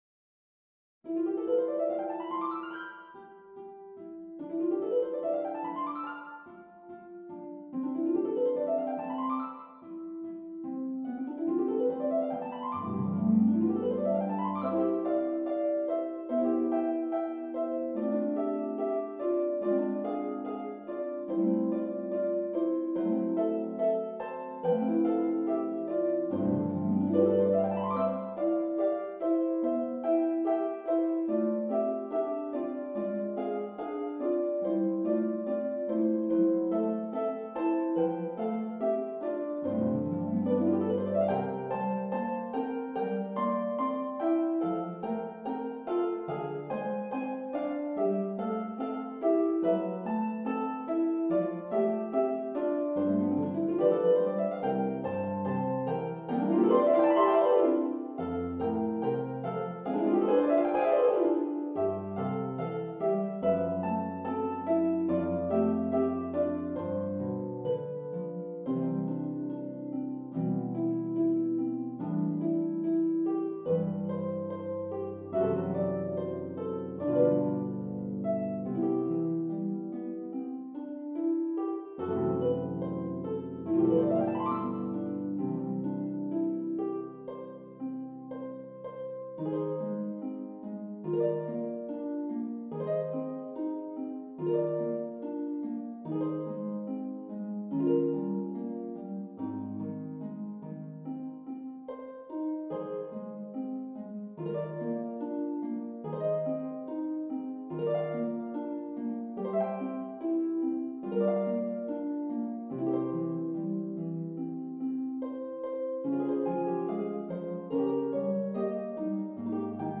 for harp duet
Harp Duo